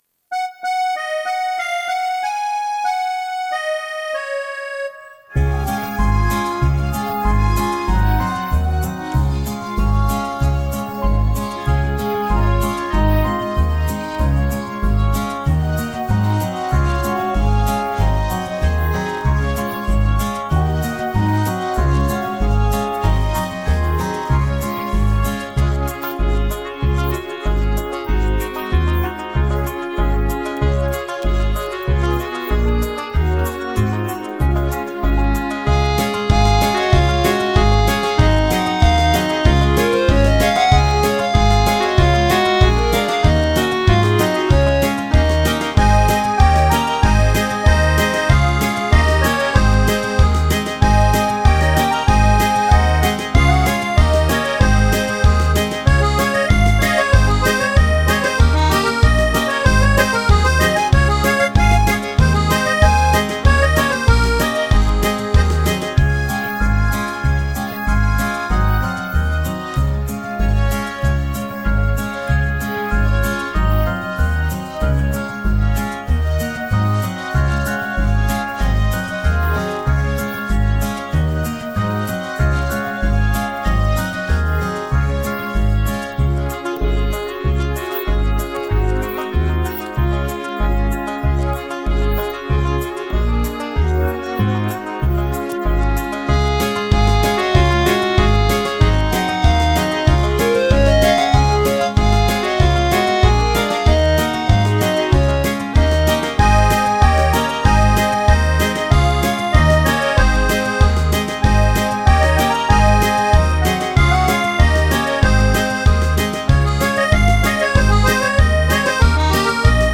Главная / Песни для детей / Песни про осень
Слушать или скачать минус